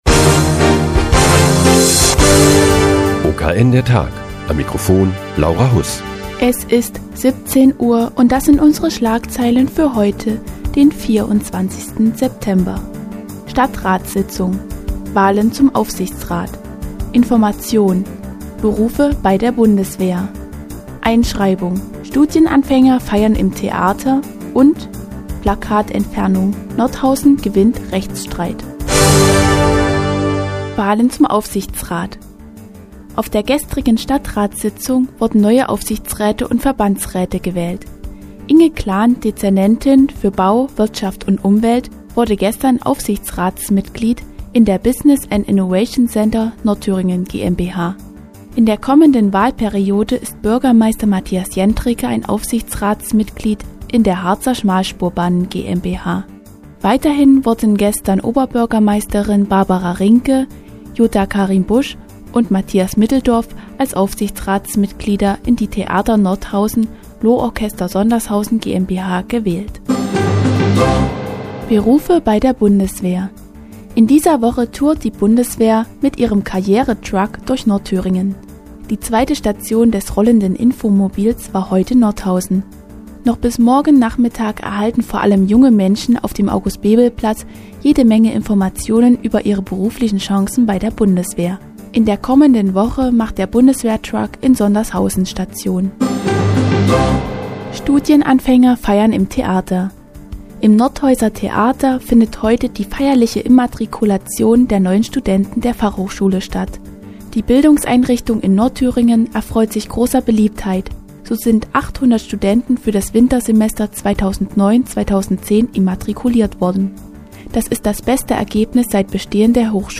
Die tägliche Nachrichtensendung des OKN ist nun auch in der nnz zu hören. Heute geht es um die Wahlen zum Aufsichtsrat und um eine Informationsveranstaltung der Bundeswehr.